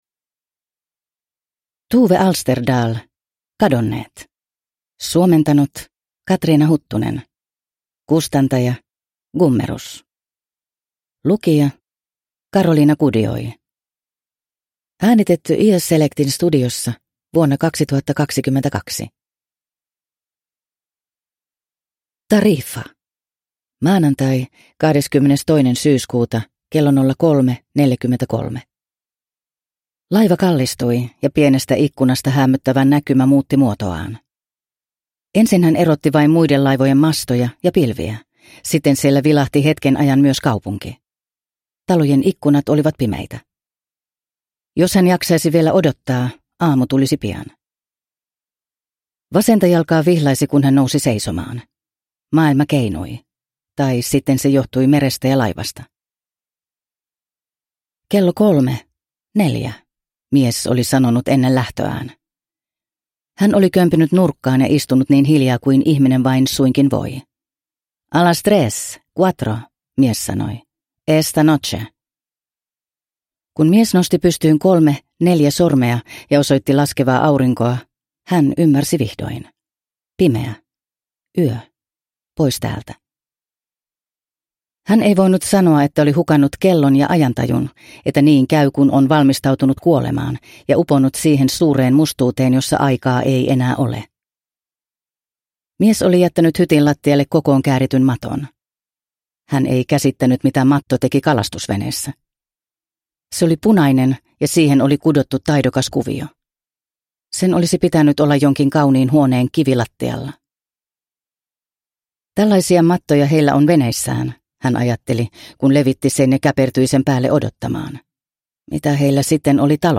Kadonneet – Ljudbok – Laddas ner